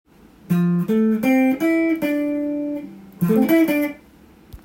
Dm7のコード上で使えるフレーズをtab譜にしてみました。
２～３本ほどの弦をさらっと弾くフレーズになります。